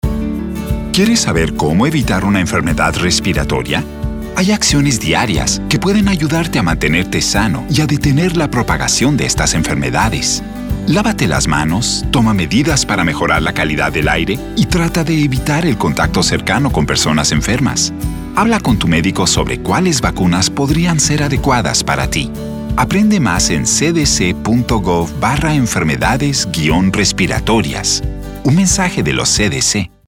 • Use these radio PSAs and scripts to share prevention strategies people can use to protect themselves from respiratory illnesses, like flu, RSV, and COVID-19.